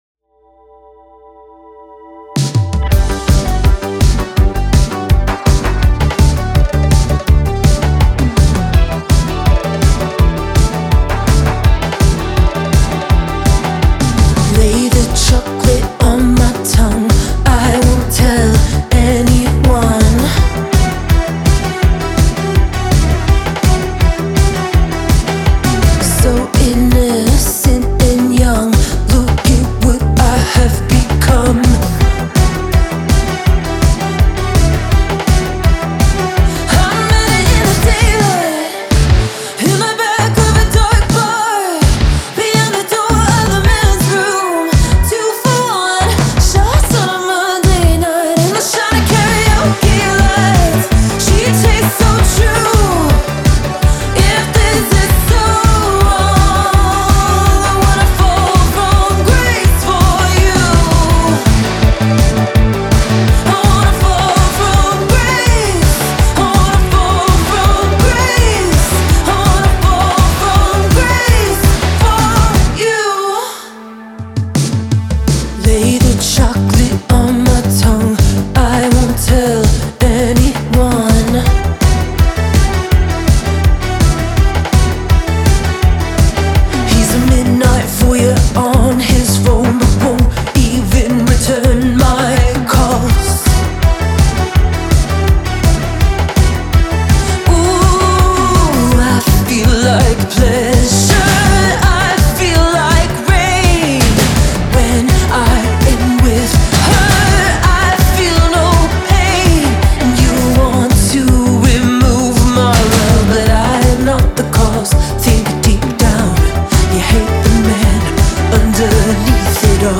Трек размещён в разделе Зарубежная музыка / Альтернатива.